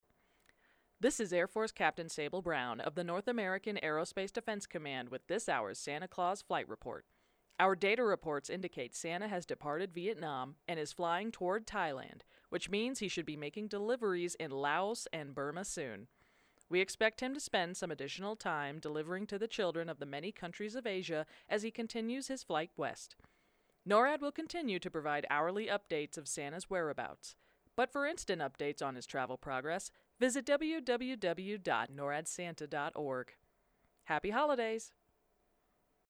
NTS 9am MTS Radio update
NORAD Tracks Santa radio update to be aired at 9am MTS on December 24, 2022